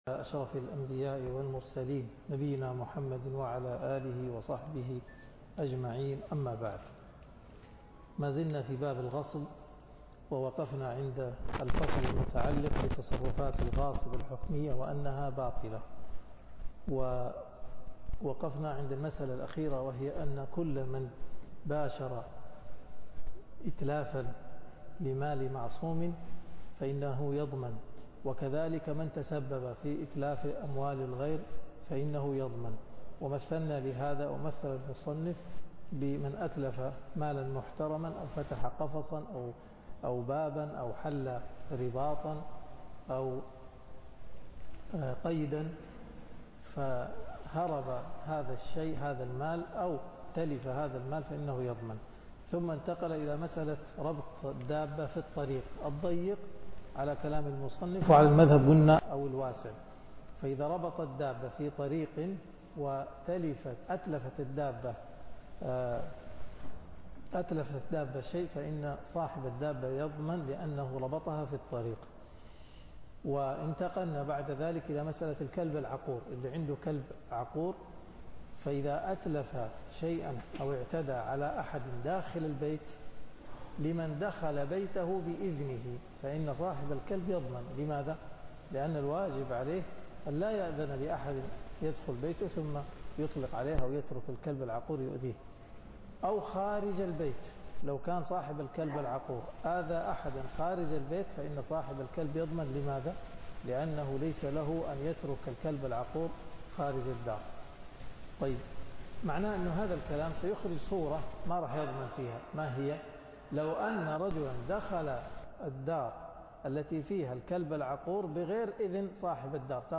الدرس (60)من قوله وما أتلفت البهيمة من الزرع ليلاً ضمنه صاحبها إلى نهاية باب الشفعة-شرح زاد المستقنع